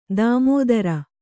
♪ dāmōdara